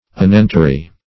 Search Result for " unguentary" : The Collaborative International Dictionary of English v.0.48: Unguentary \Un"guen*ta*ry\, a. [L. unguentarius.] Like an unguent, or partaking of its qualities.
unguentary.mp3